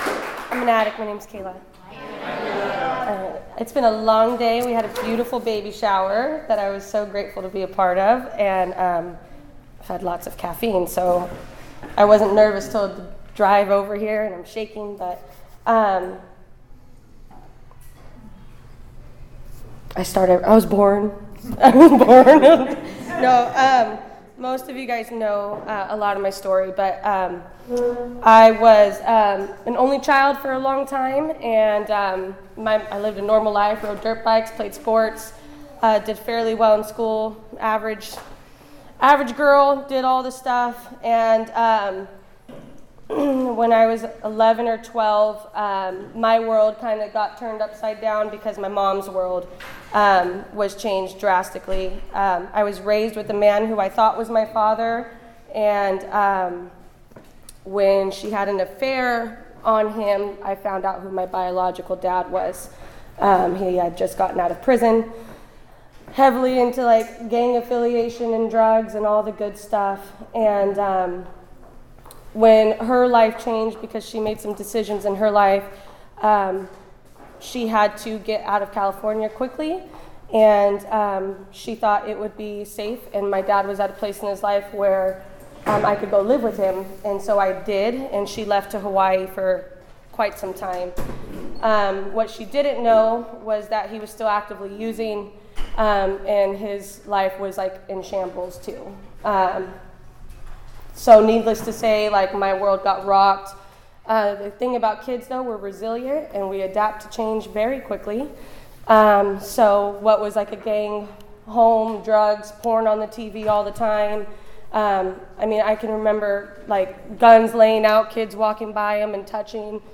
New Beginnings Speaker Meeting